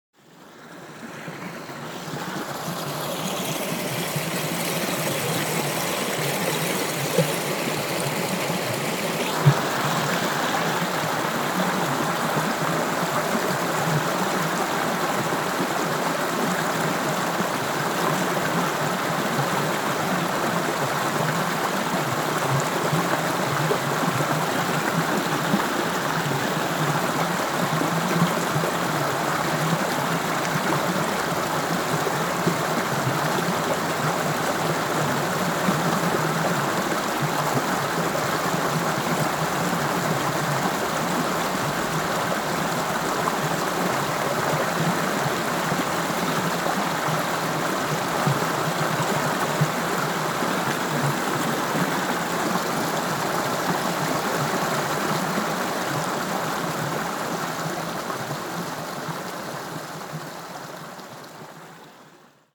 bruit d'eau.mp3